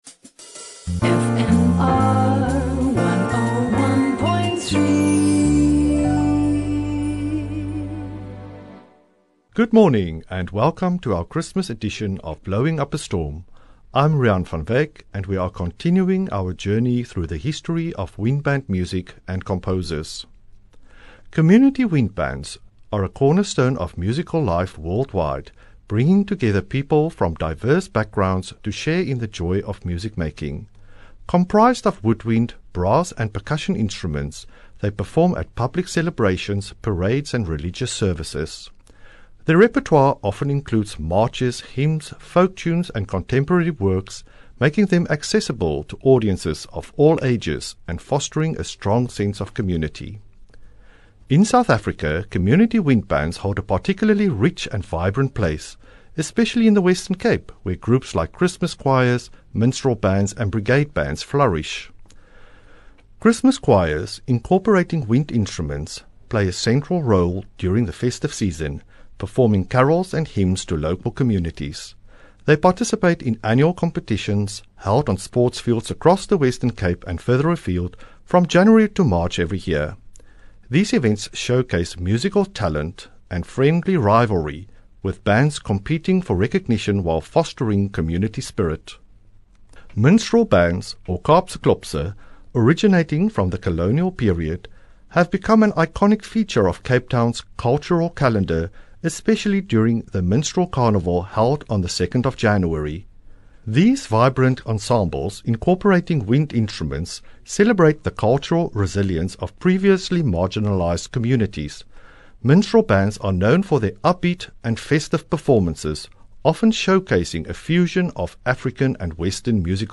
Extracts from Christmas melodies performed by the Immanuel Sacred, Wynberg Progress and Perseverance Immanuel Sacred Christmas Bands.